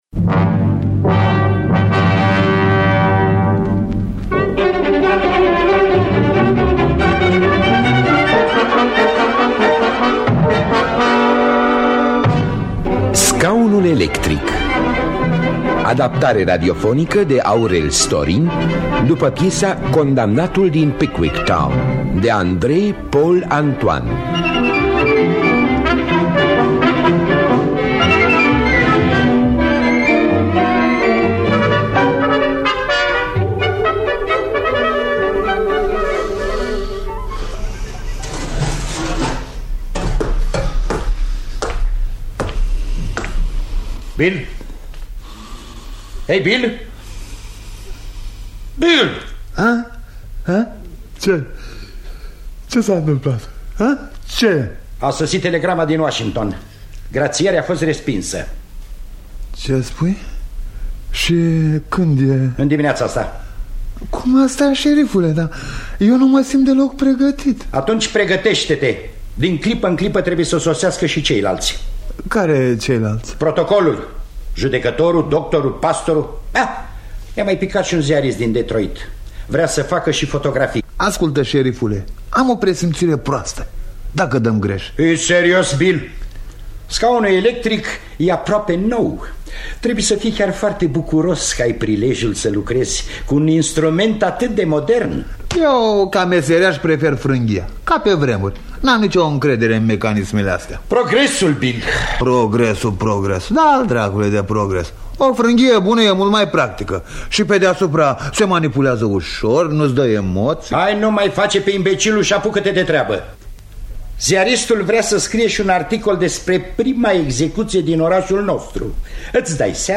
Teatru Radiofonic Online
Adaptarea radiofonică
Înregistrare din anul 1966